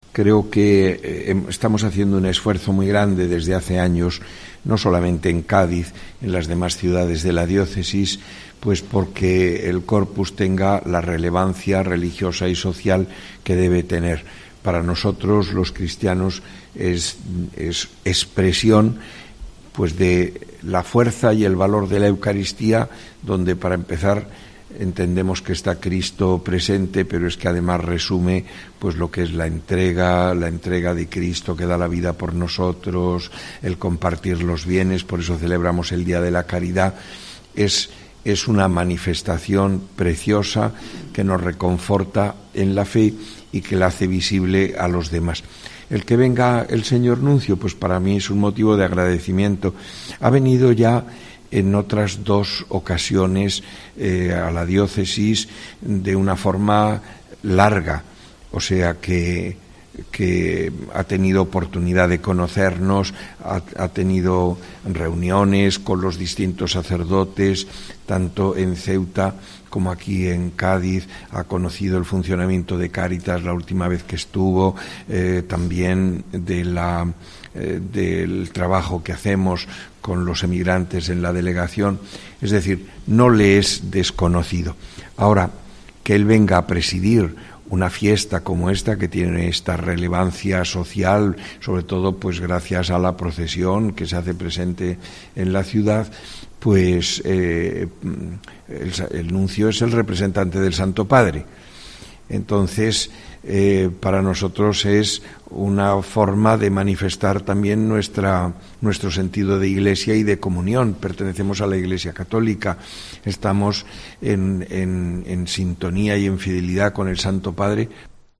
Monseñor Rafael Zornoza, obispo de Cádiz y Ceuta ante la celebración del Coprus Christi 2023